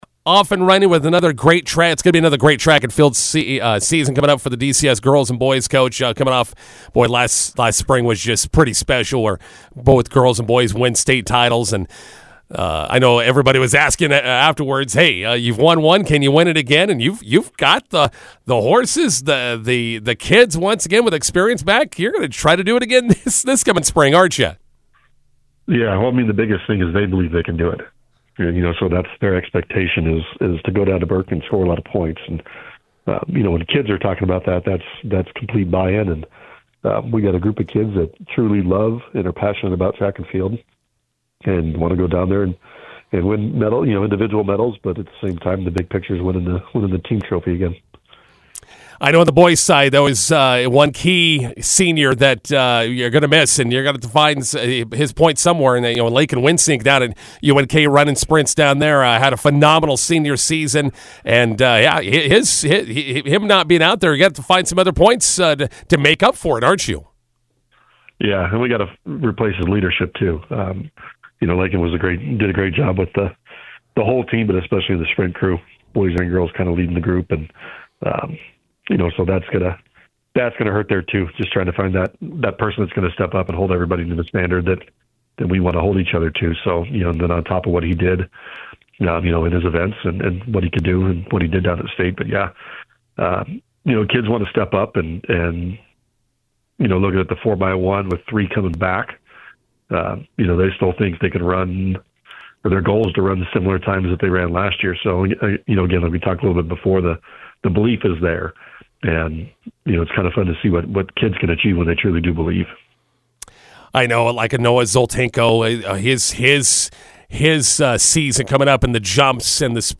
INTERVIEW: Dundy County-Stratton track and field looking for back-to-back state titles.